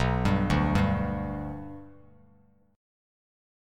Bm#5 chord